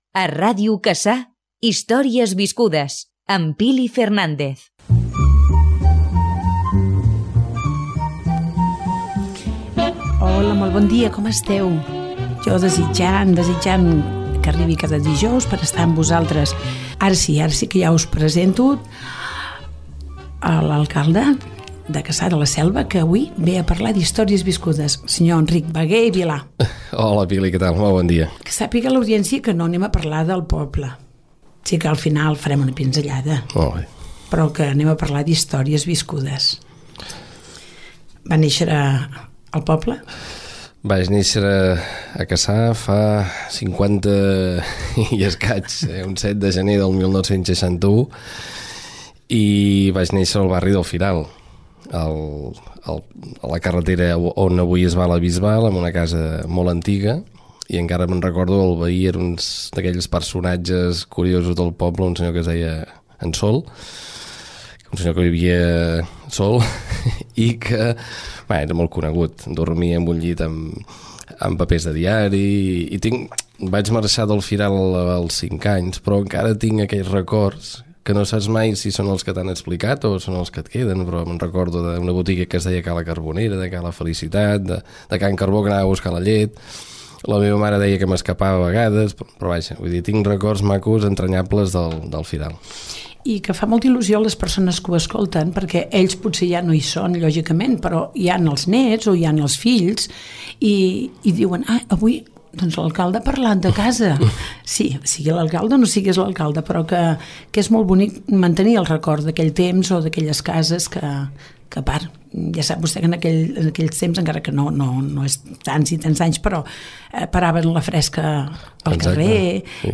Identificació del programa, presentació i entrevista a l'alcale de Cassà, Enric Bagué, sobre la seva infància i joventut al poble
Entreteniment